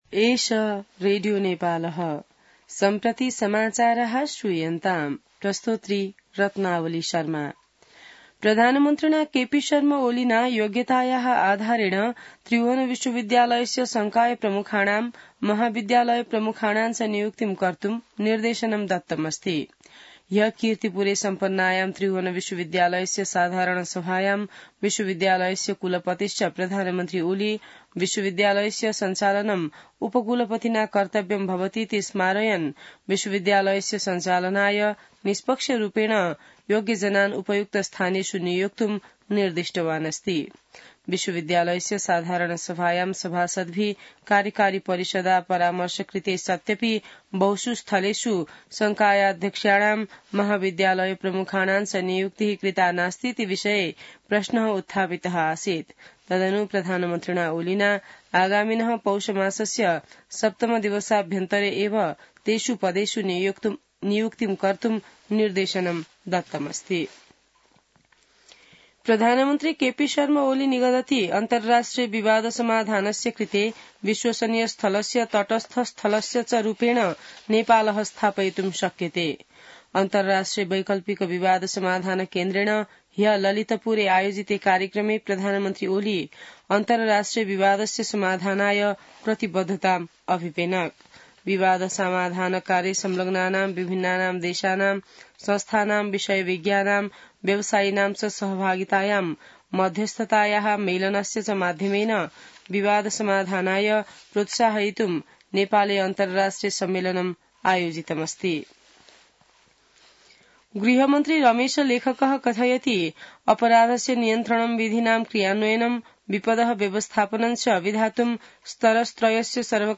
संस्कृत समाचार : २९ मंसिर , २०८१